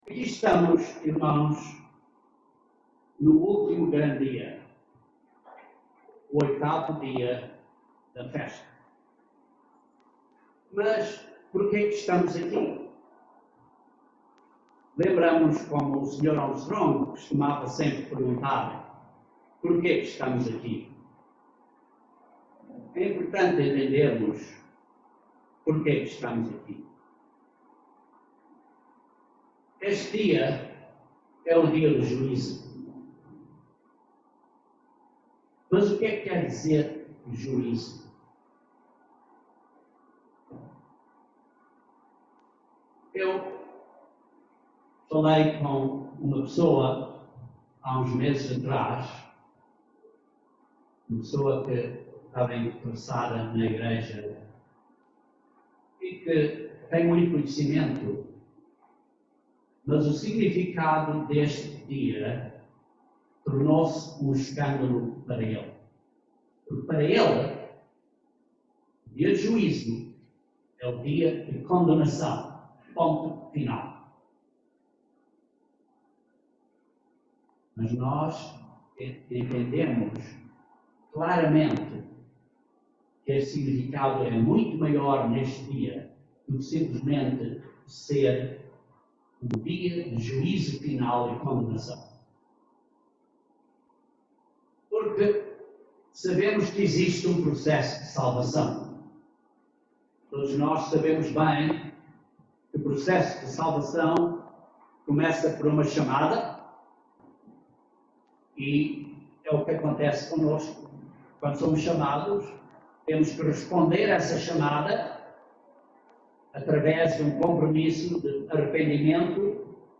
O significado do Último Grande Dia da Festa, o Oitavo Dia, é explicado neste sermão.